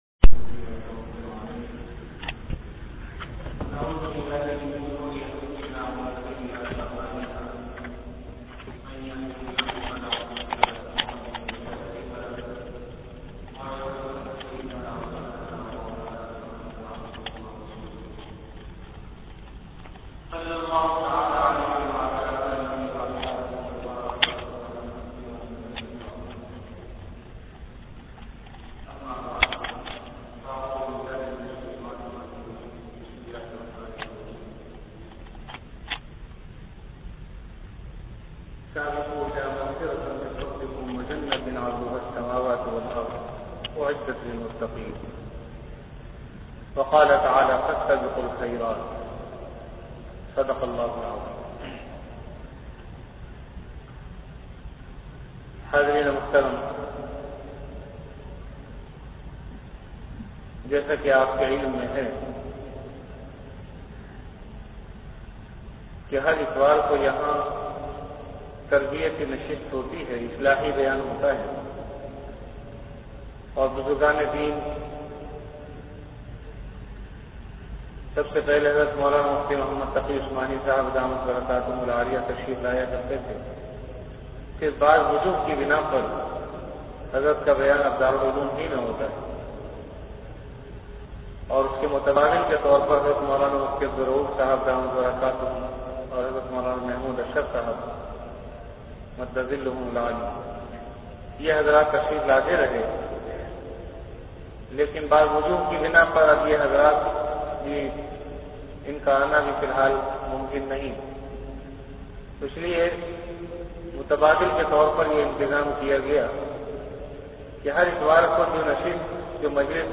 Delivered at Jamia Masjid Bait-ul-Mukkaram, Karachi.
Bayanat · Jamia Masjid Bait-ul-Mukkaram
After Asar Prayer